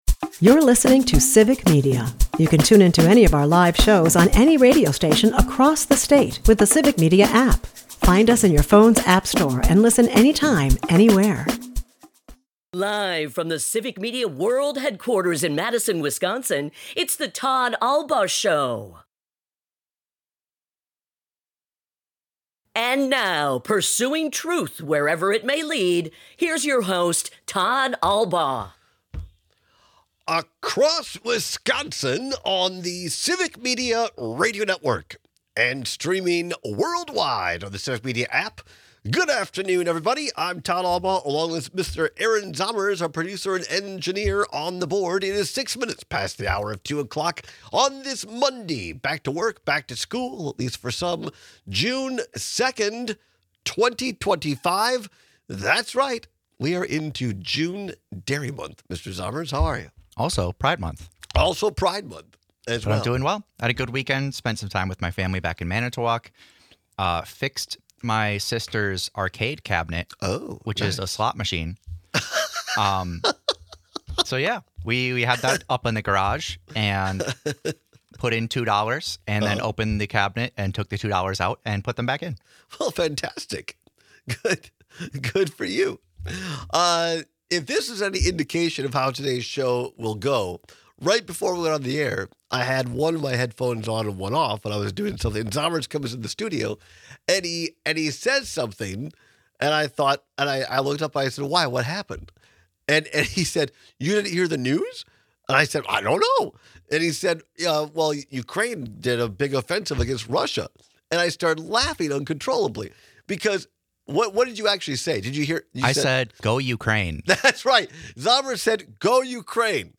At the bottom of the hour, State Senator Kelda Roys returns for another update on the Joint Finance Committee’s state budget deliberations. Sen. Roys informs us that the GOP members of the JFC continue to hold up the process as the deadline creeps ever closer.